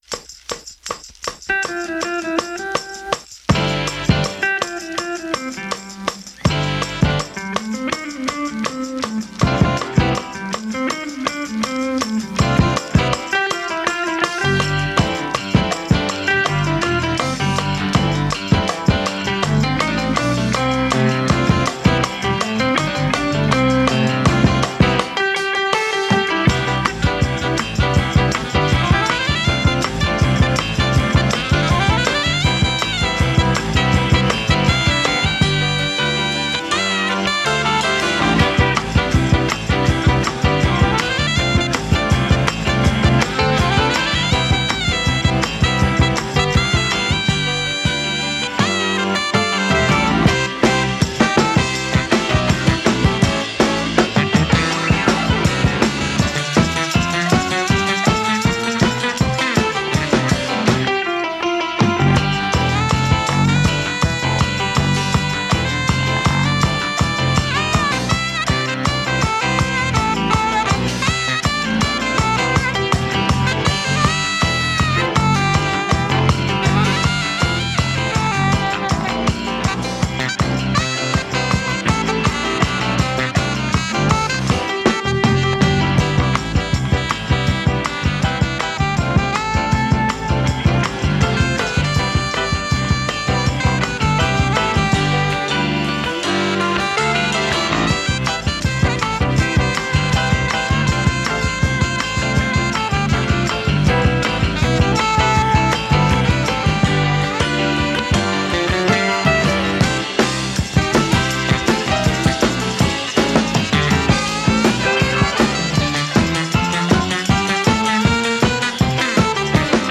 acoustic piano.
saxophone.
electric guitar.
electric bass.
drums.